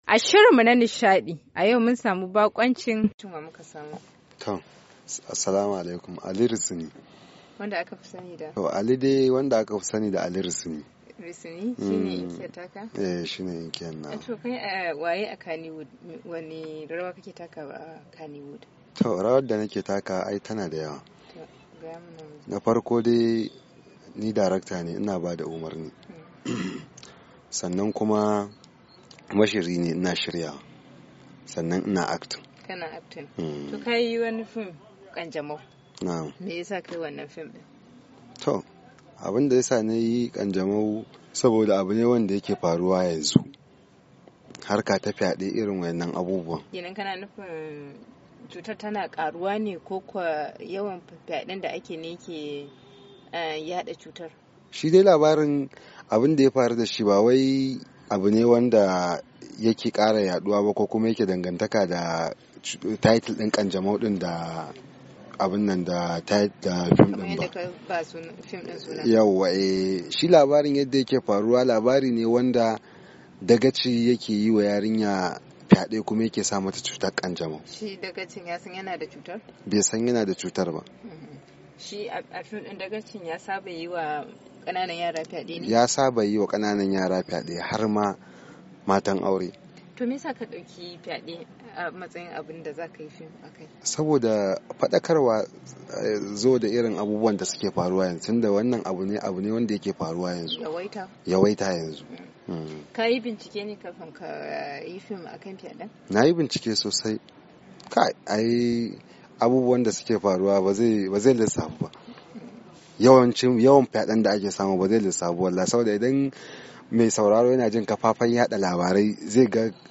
ya bayyana haka ne a zantawarsa